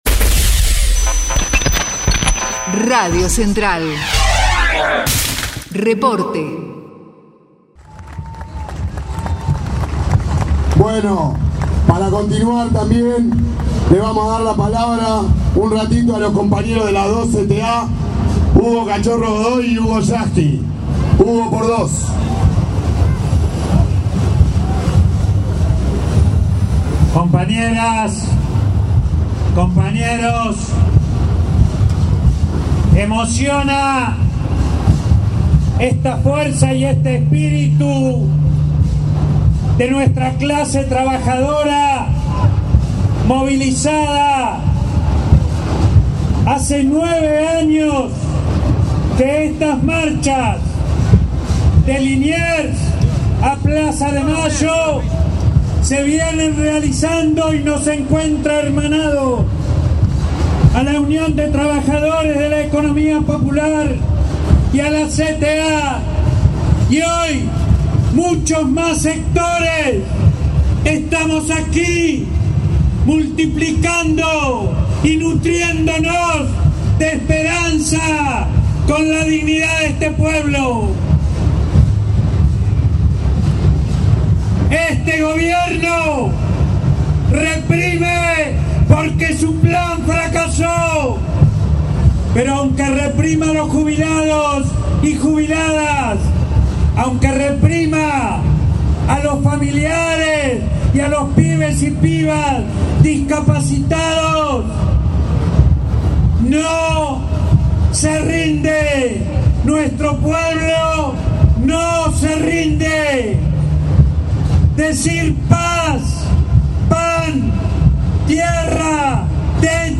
MARCHA Y ACTO X SAN CAYETANO: Testimonios CTA en Plaza de Mayo